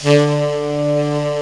Index of /90_sSampleCDs/Giga Samples Collection/Sax/HARD + SOFT
TENOR SOFT D.wav